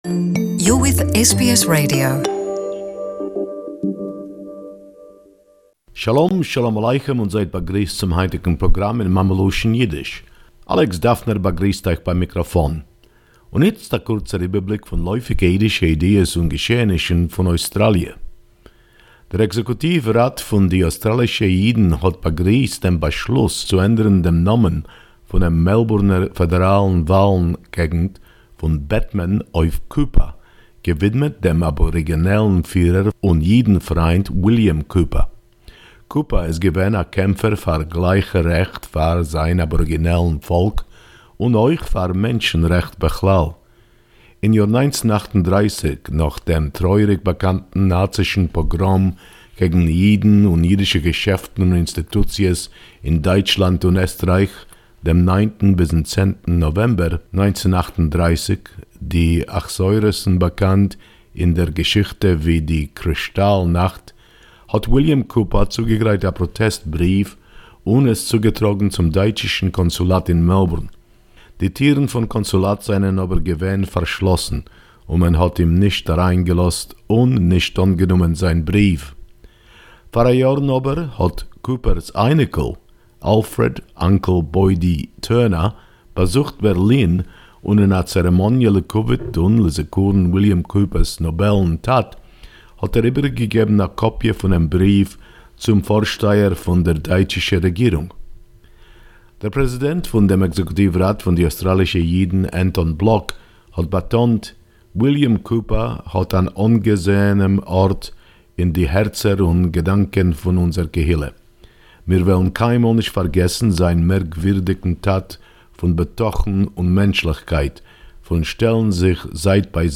Yiddish report for the latest in the Jewish community 1.7.2018